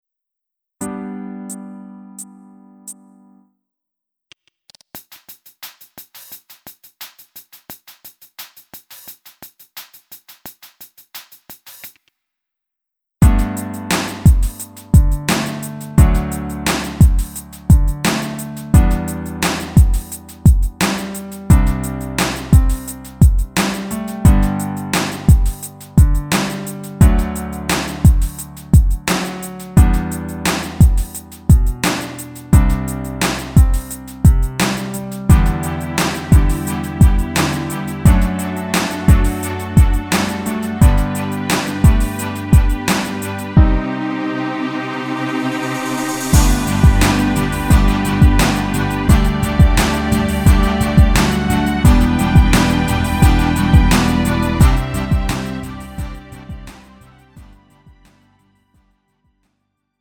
음정 원키 3:21
장르 가요 구분 Lite MR